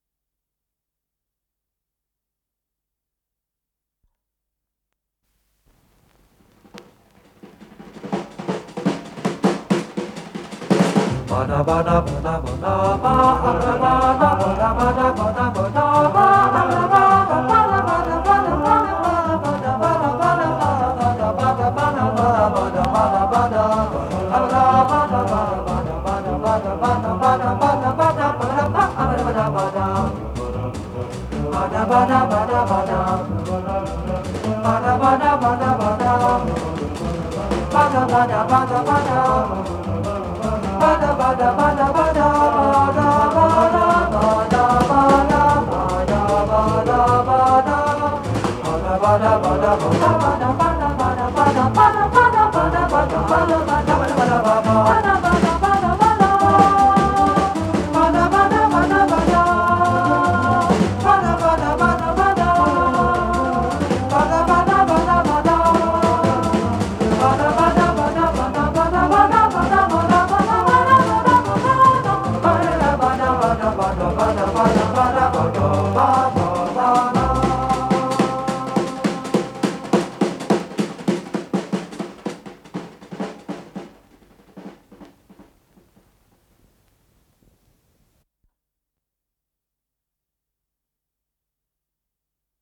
с профессиональной магнитной ленты
ПодзаголовокДо мажор
ИсполнителиВокально-инструментальный ансамбль "Свингл Сингерс"
ВариантДубль моно